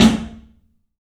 PABSNARE102L.wav